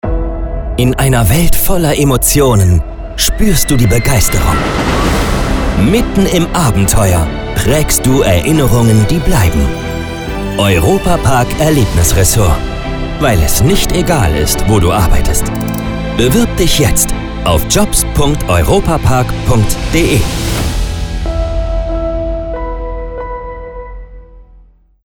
Funkspot – deutsche Version DE